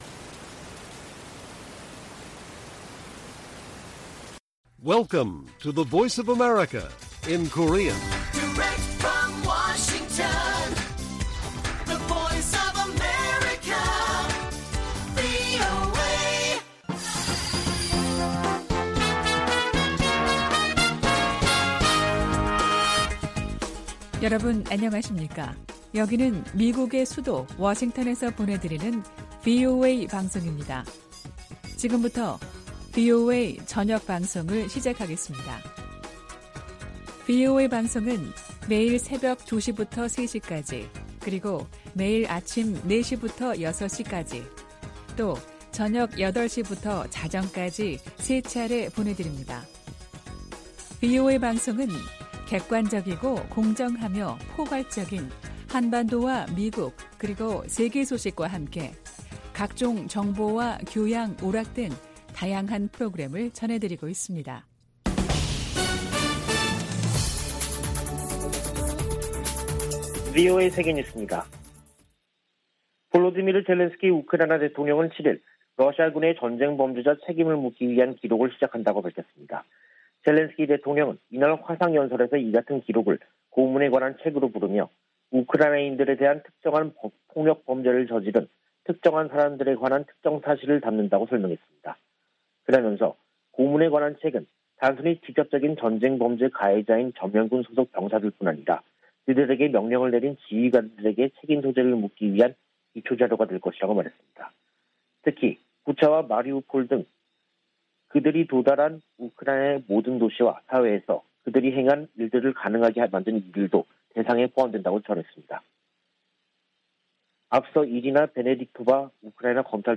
VOA 한국어 간판 뉴스 프로그램 '뉴스 투데이', 2022년 6월 8일 1부 방송입니다. 미·한·일 외교차관들이 서울에서 만나 북한의 핵과 미사일 위협의 고도화에 대응해 안보협력을 강화하기로 했습니다. 북한이 풍계리에서 7차 핵실험 준비를 마쳤으며 언제라도 실험에 나설 수 있다고 성 김 미 대북특별대표가 밝혔습니다. 북한이 코로나 사태에 국제사회의 지원을 받으려면 현장 접근과 물자 반입, 국제직원 상주 등을 허용해야 한다고 유럽연합이 지적했습니다.